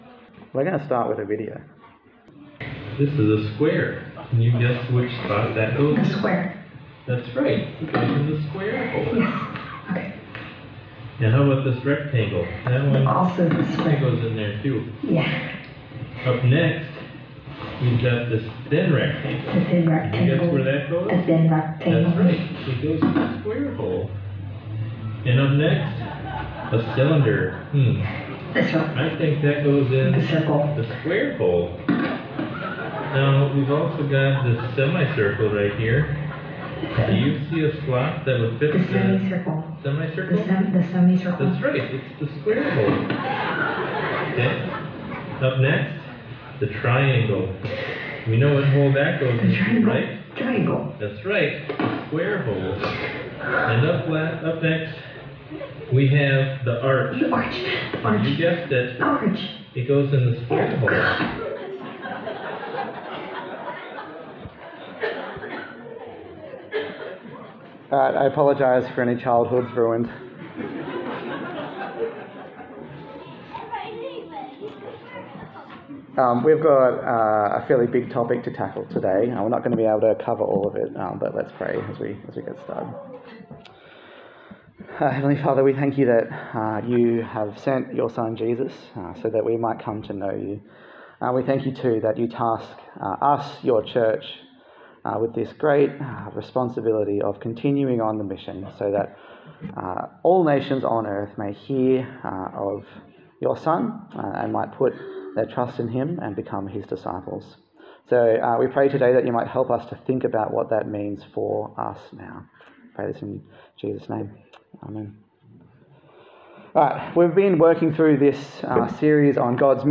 A sermon in the series on God's Mission
Service Type: Sunday Morning